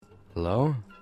Louder Hello Sound Effect Free Download
Louder Hello